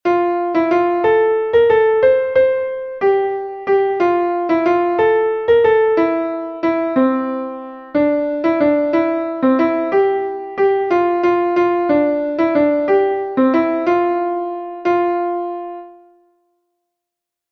Entoación a capella
Melodia 6/8 en Fa M